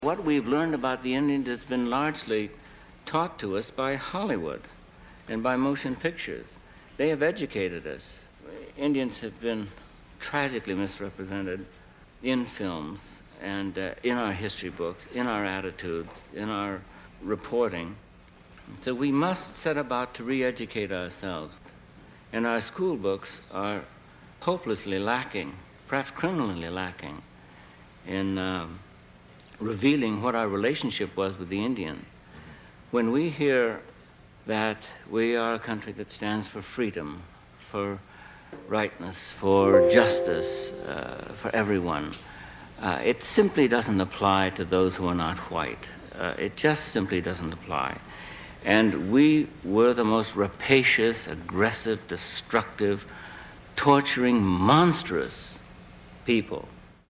Lo stesso Marlon Brando spiegò pochi mesi dopo, in un’intervista, quale fosse stato il ruolo di Hollywood in una rappresentazione dei nativi americani che lui stesso definì criminale.
2-Vox-Marlon-Brando.mp3